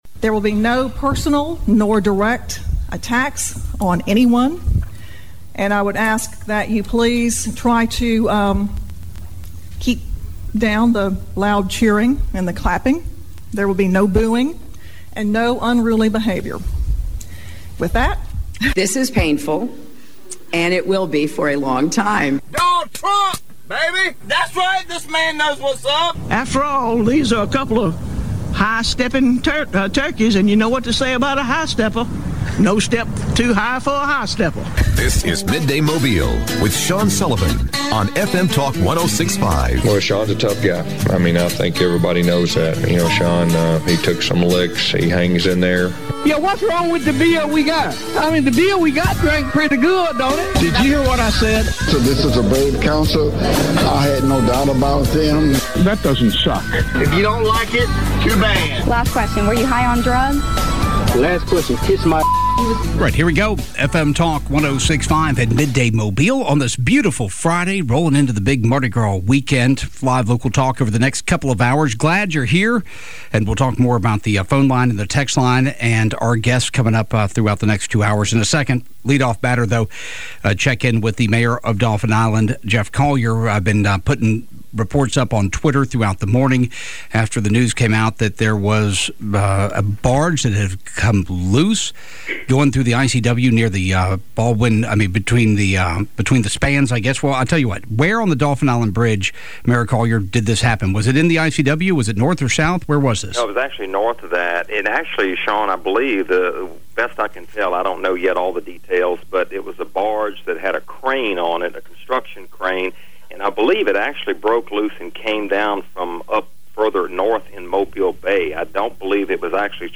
Alabama District 95 State Rep. Steve McMillan joins the show.